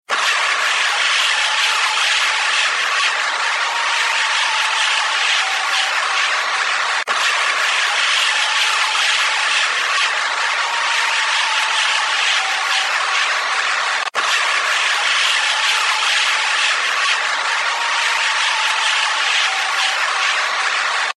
Category: Animals/Nature   Right: Personal
Tags: Wildlife audio recordings Unknow Wildlife Souns